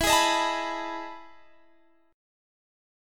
EM11 Chord
Listen to EM11 strummed